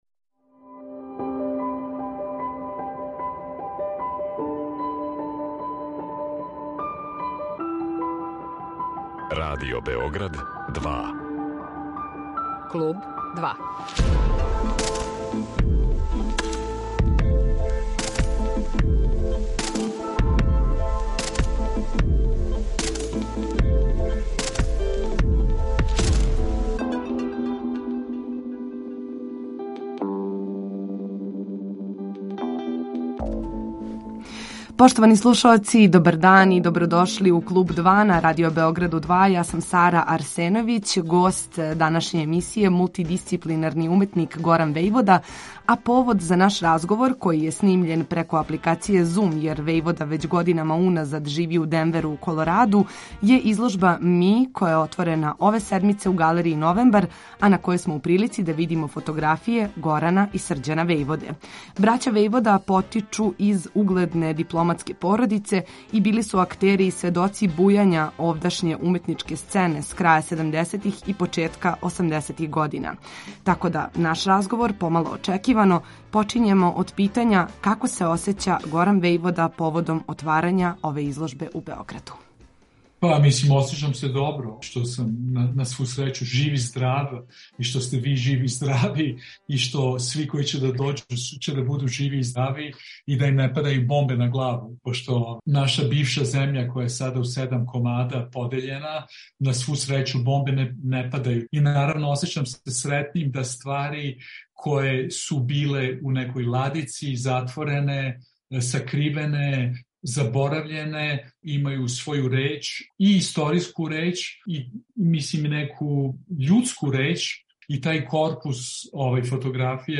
снимљен преко апликације Зум